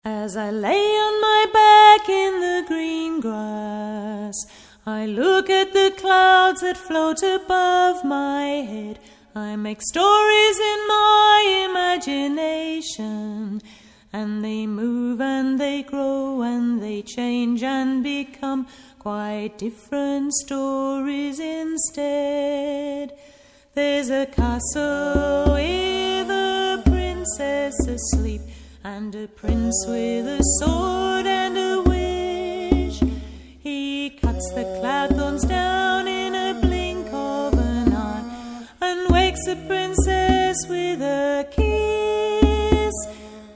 This is a collection of relaxing songs for children
a cappella choir
Uillean pipe player